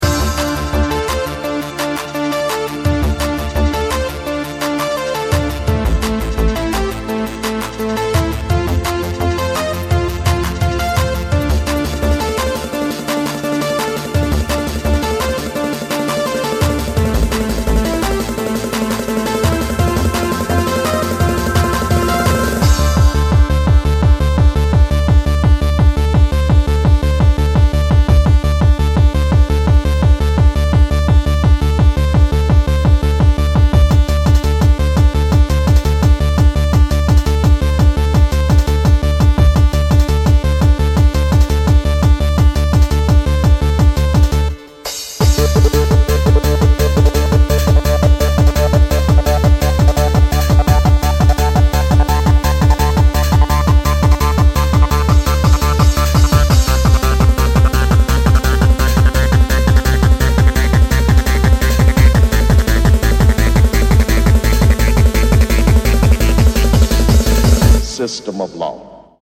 Hard-Trance